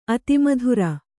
♪ ati madhura